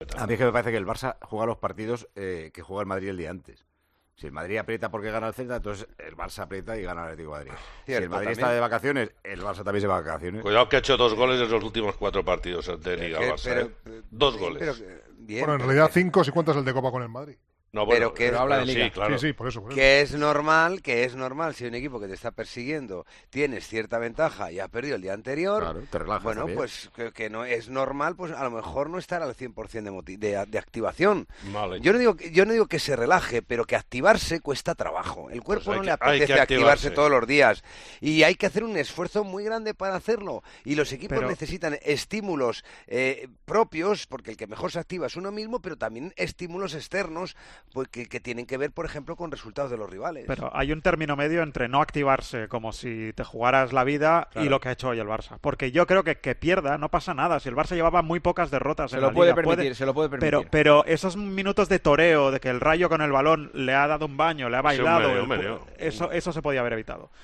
El presentador de 'Tiempo de Juego' explicó en 'El Partidazo de COPE' lo que cree que sucede con los pinchazos del equipo de Xavi Hernández